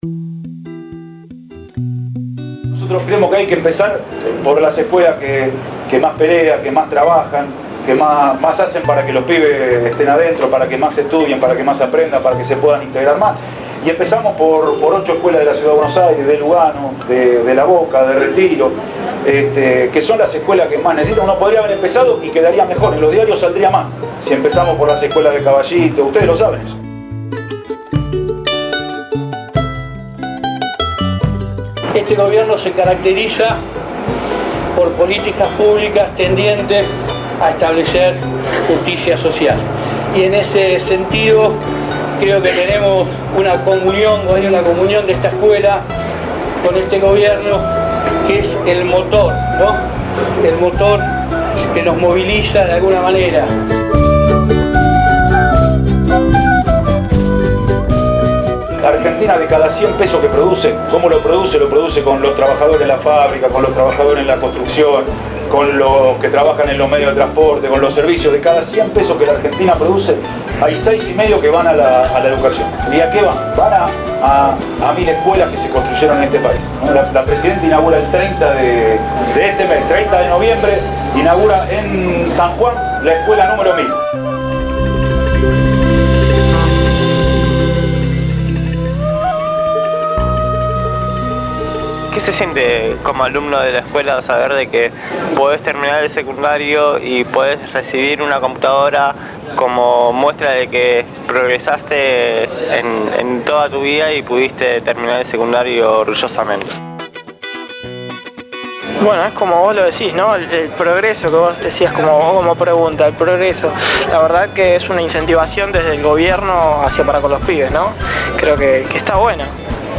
Radio Gráfica estuvo en el emocionante acto, que se desarrolló en el marco de la muestra anual de talleres de la escuela.